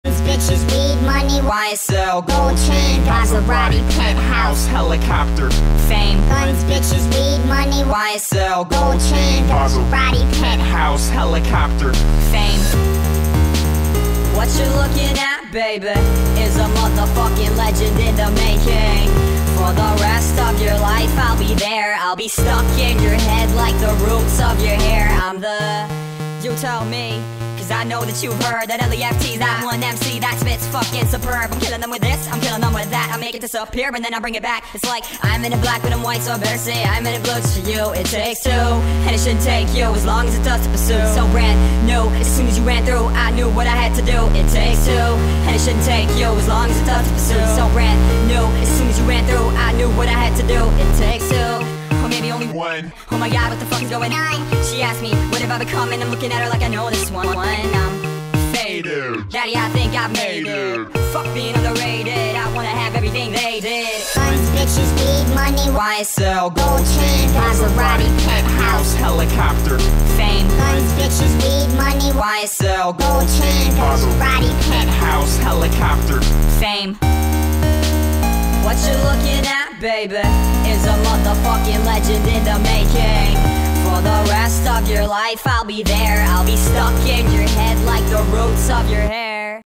sped up remix